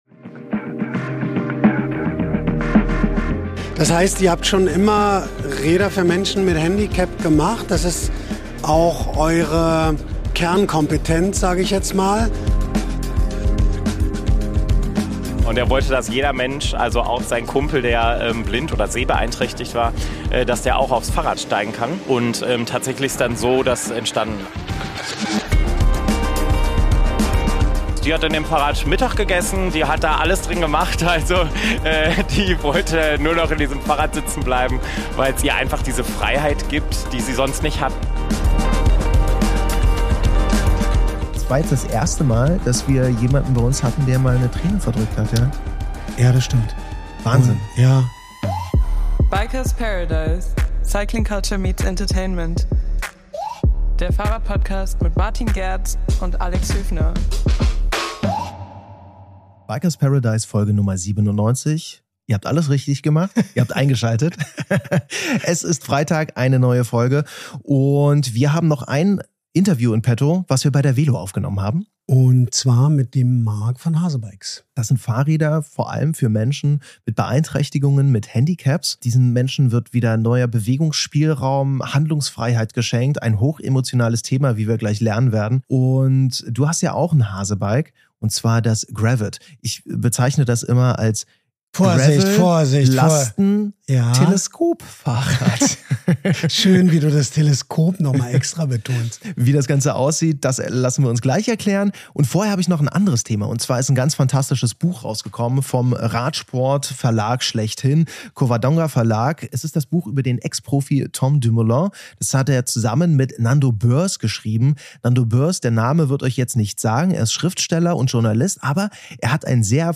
In unserem letzten Interview von der Velo, reden wir zusammen mit Hasebikes über ihr eigentliches Spezialgebiet: Inklusion auf dem Rad! Von verschiedenen Modellen, über berührende Geschichten von Kunden, die Geschichte des Unternehmens und sogar streng geheime Infos!